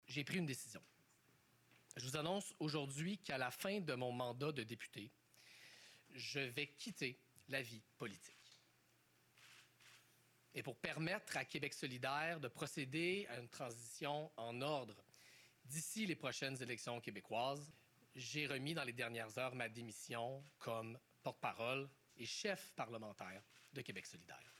M. Nadeau-Dubois en a fait l’annonce lors d’une conférence de presse, jeudi après-midi, affirmant qu’il préférait laisser sa place «pour permettre à de nouvelles figures d’émerger
Il a précisé les raisons de sa décision en conférence de presse, affirmant que les crises de son parti avaient laissé des traces chez lui.